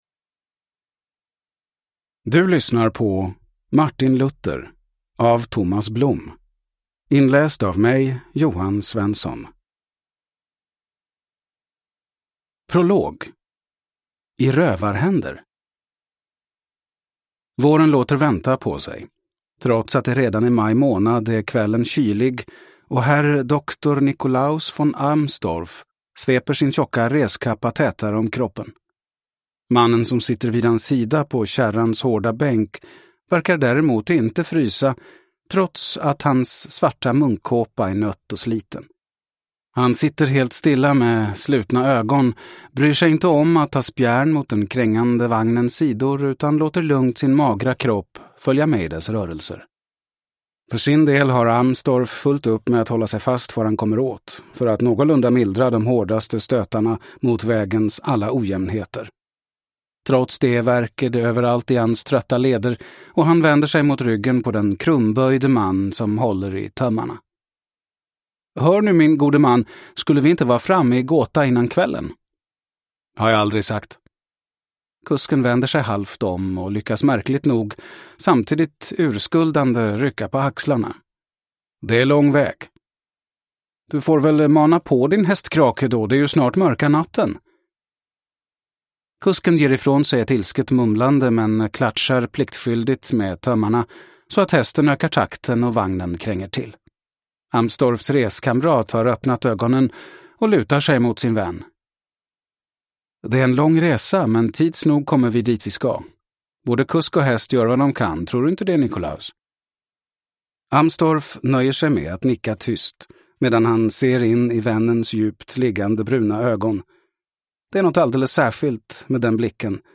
Martin Luther – Ljudbok